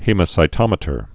(hēmə-sī-tŏmĭ-tər)